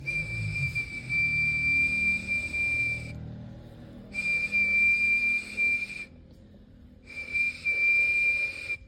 Depois, construíram uma réplica do artefato, também feita de osso de vaca, e testaram para verificar se, de fato, produziria som.
A ideia de que o apito pudesse ser utilizado como instrumento musical foi descartada porque ele só produz uma nota.
a-tiny-cow-bone-whistl.mp3